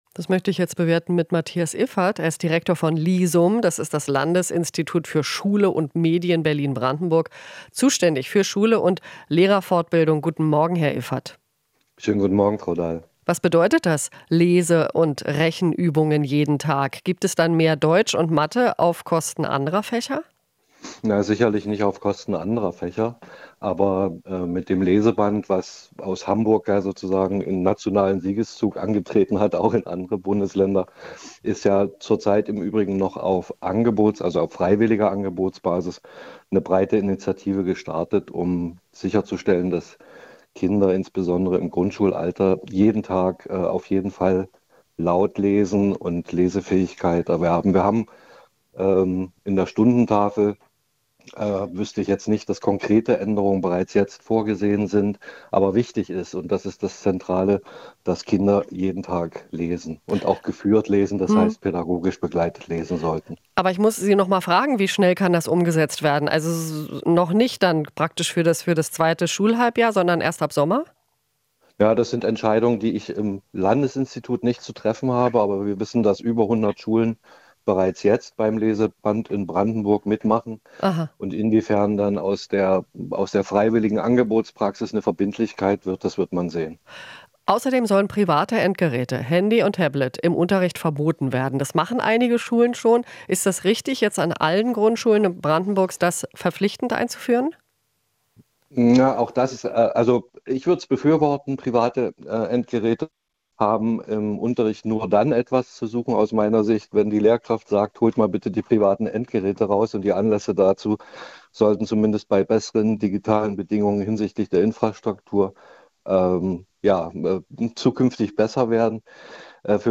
Interview - Experte begrüßt Bildungspläne der neuen Brandenburger Regierung